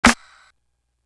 Carved In Stone Snare.wav